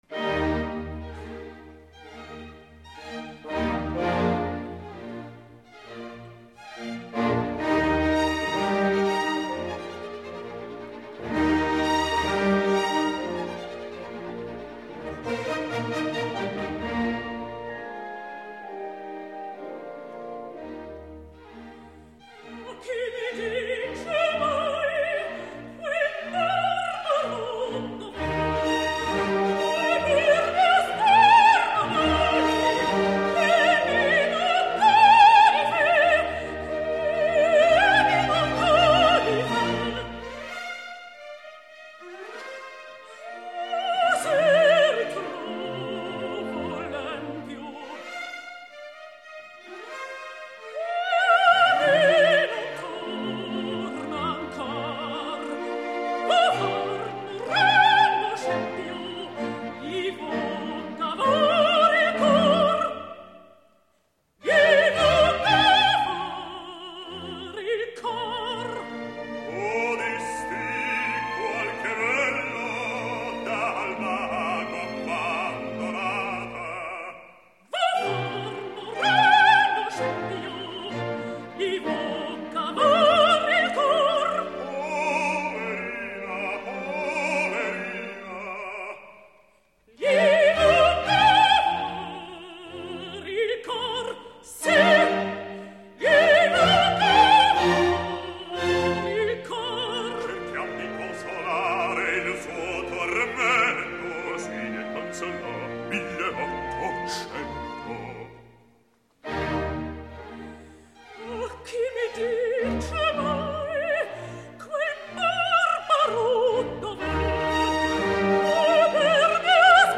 Запись: июнь-июль 1966 года, Лондон.